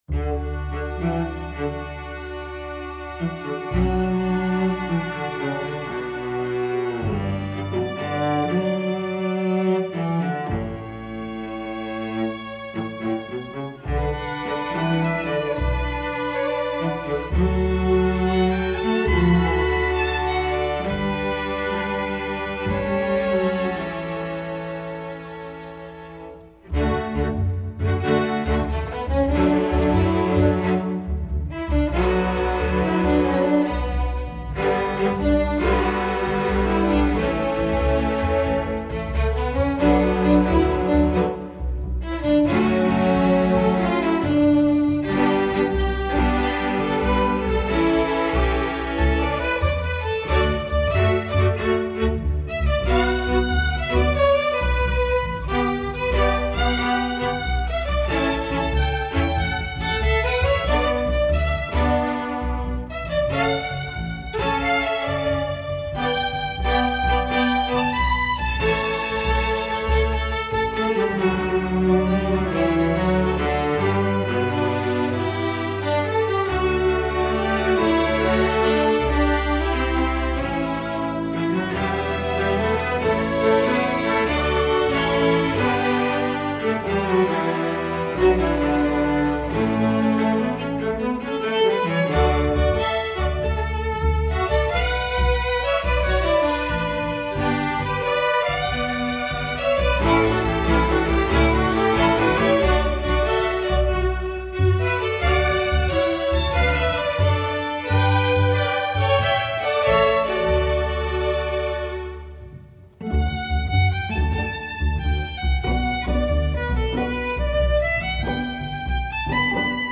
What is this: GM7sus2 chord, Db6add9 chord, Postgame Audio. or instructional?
instructional